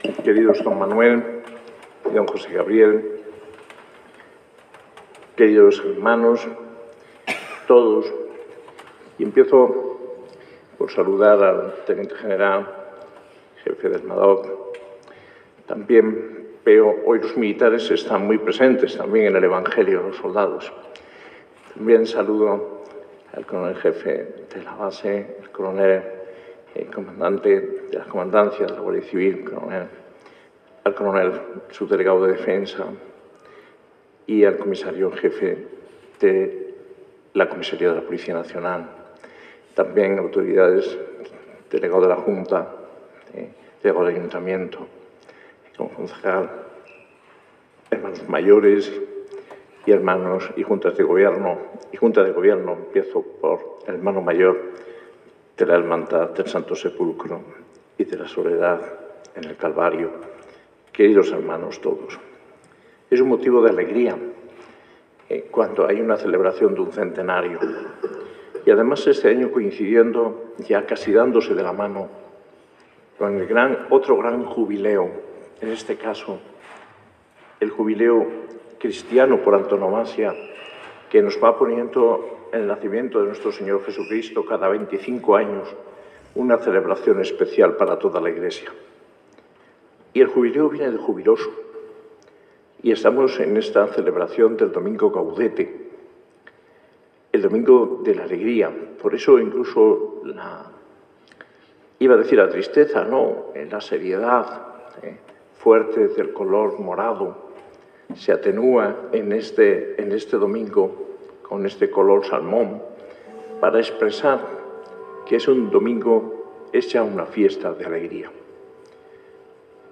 En vísperas del domingo III de Adviento, Domingo Gaudete (de la alegría), el arzobispo Mons. José María Gil Tamayo celebraba la Eucaristía en la parroquia de San Gil y Santa Ana, en pleno centro de Granada, en Plaza Nueva.
En sus palabras durante la homilía, Mons. Gil Tamayo habló de la alegría que caracteriza al cristiano y no el pesimismo, que es algo pasajero, explicó. También habló de María, Madre de Dios con una invitación a que esté presente en nuestras “vivencias existenciales”. ( ESCUCHAR HOMILÍA EN ESTE ENLACE )